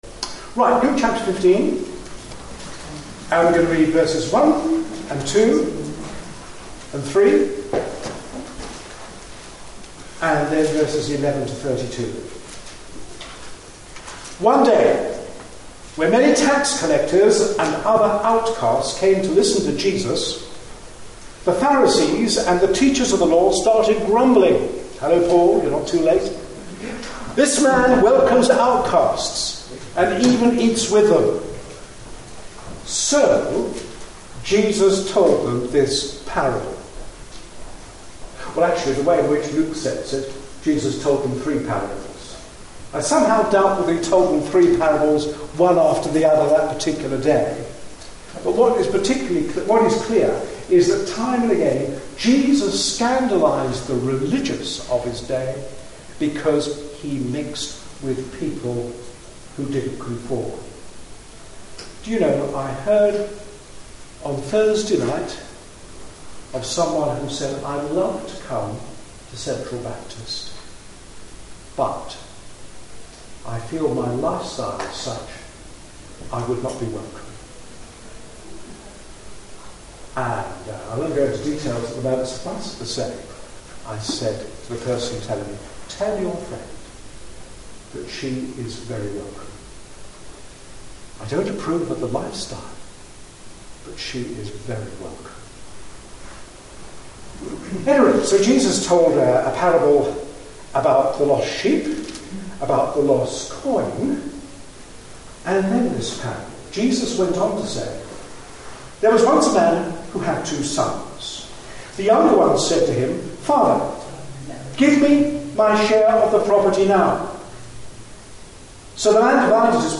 A sermon preached on 2nd October, 2011.
Luke 15:11-32 Listen online Details This was a Sunday evening talk (slightly poor quality due to being recorded in a room with no sound system).